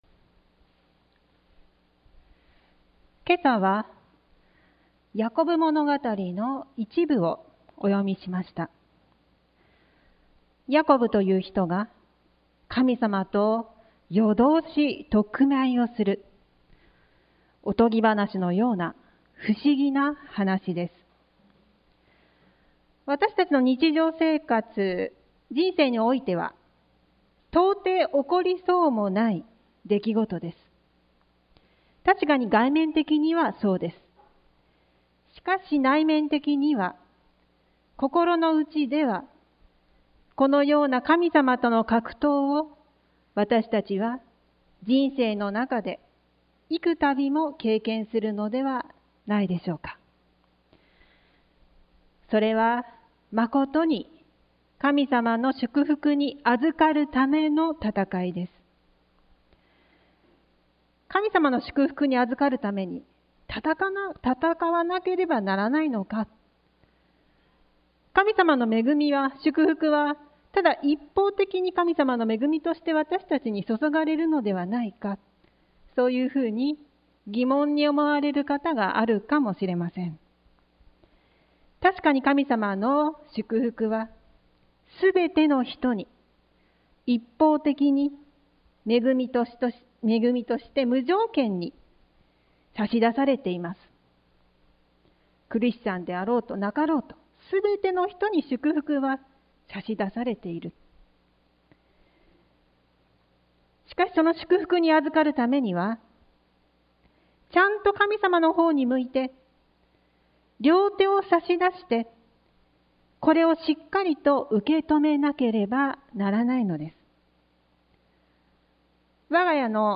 sermon-2021-08-29